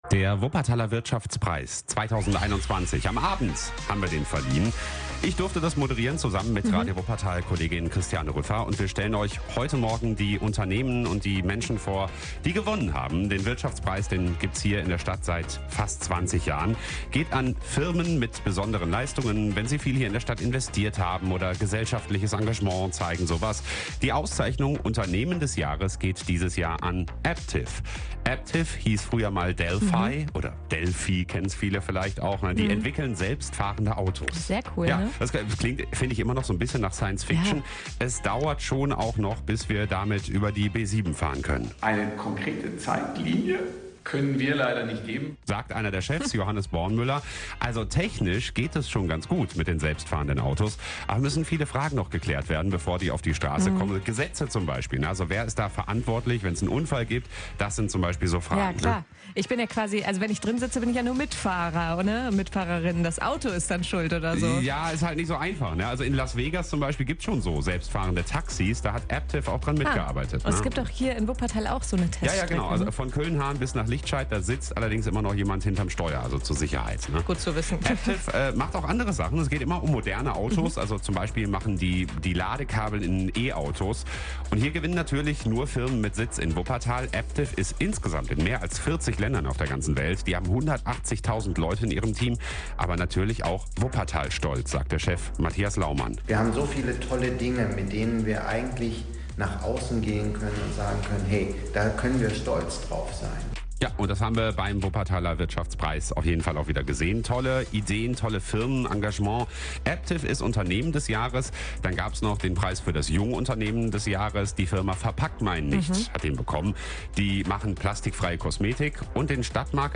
Am 27. Oktober 2021 wurden in der Glashalle der Sparkasse die Wuppertaler Wirtschaftspreise 2021 verliehen.
wuppertaler_wirtschaftspreis_mitschnitt_aptiv-unternehmen-des-jahres.mp3